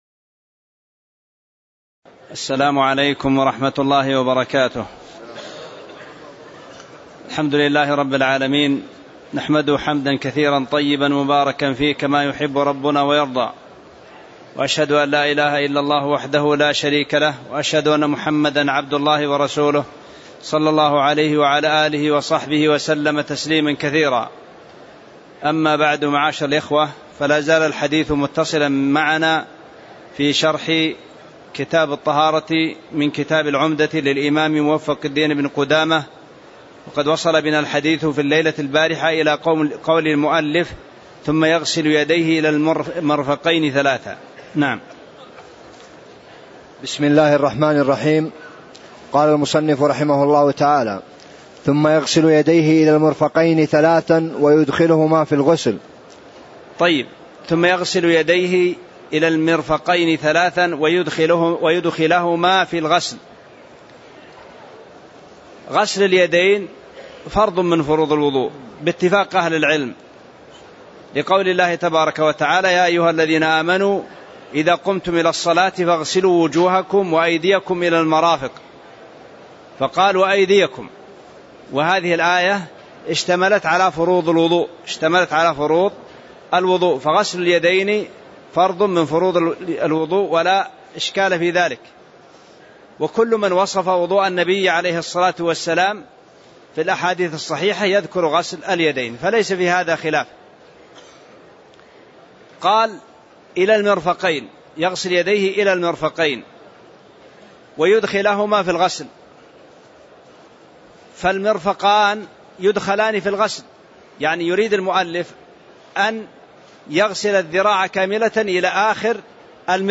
تاريخ النشر ٢٩ ذو الحجة ١٤٣٧ هـ المكان: المسجد النبوي الشيخ